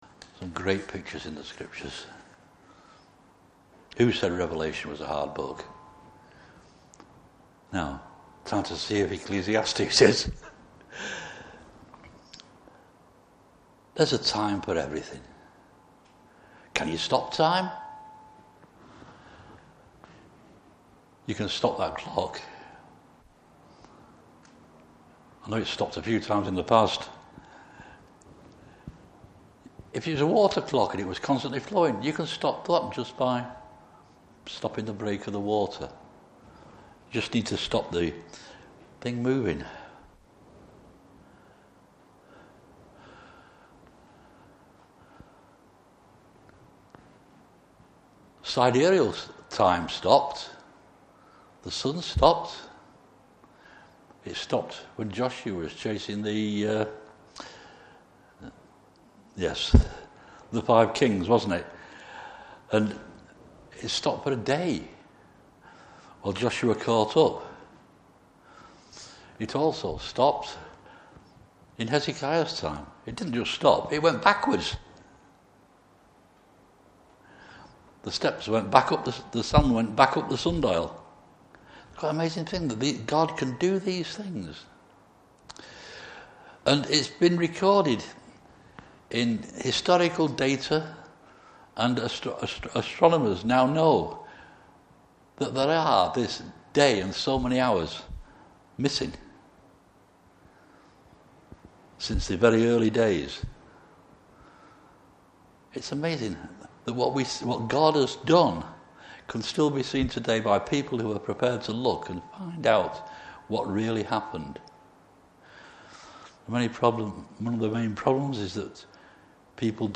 Service Type: Evening Service Bible Text: Ecclesiastes 2:24 -3:8.